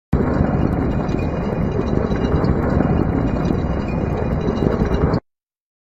scp-173-sounds-made-with-Voicemod.mp3